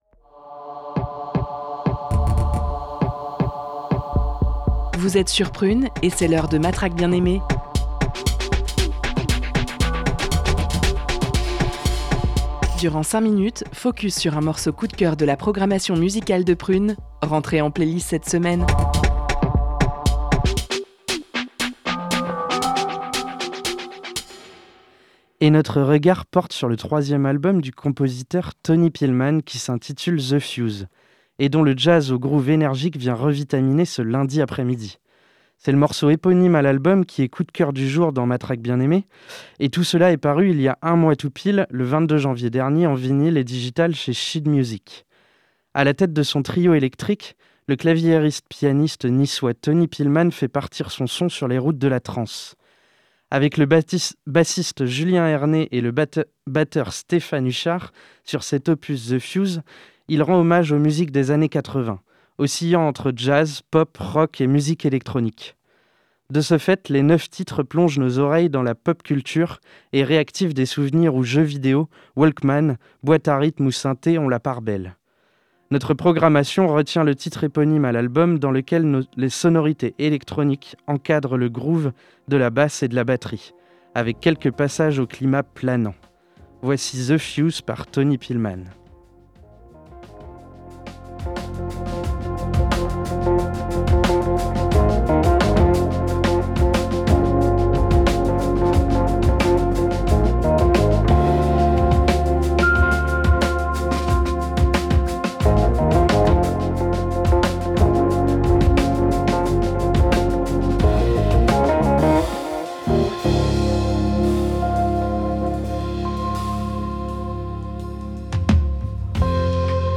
jazz au groove énergique